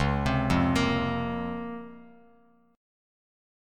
C#M13 chord